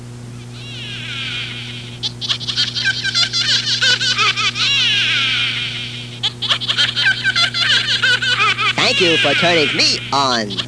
Monsters, Myths & Legends STARTUP sound: "Thank you for turning me on."
SHORT version - with witch sound effects 231k